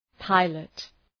Προφορά
{‘paılət}